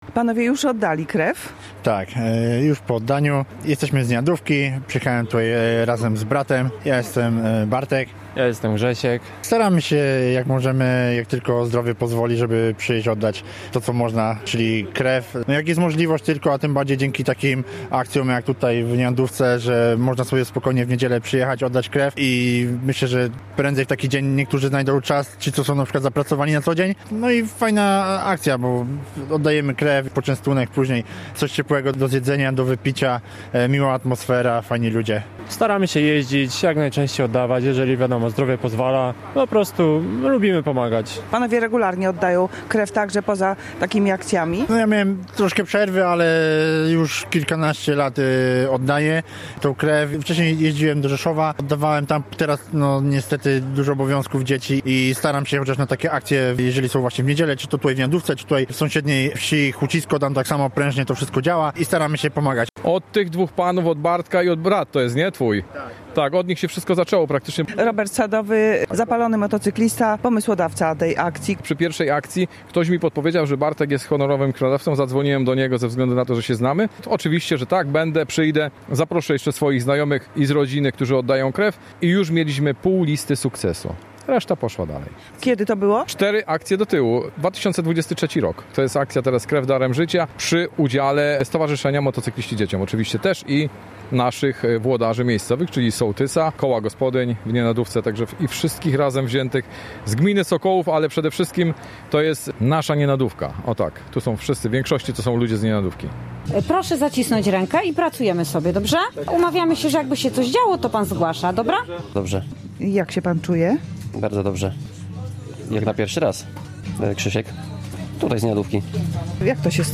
Krwiobus Regionalnego Centrum Krwiodawstwa i Krwiolecznictwa stanął w niedzielę na głównym placu wsi.